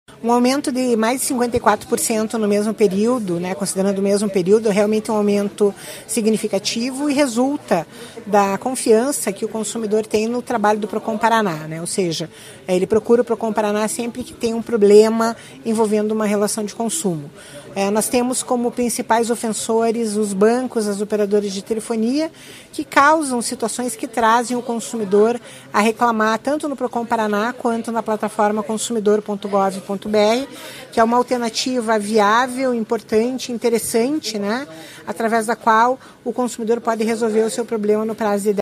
Sonora da coordenadora do Procon-PR, Claudia Silvano, sobre o aumento no número de atendimentos na primeira quinzena de janeiro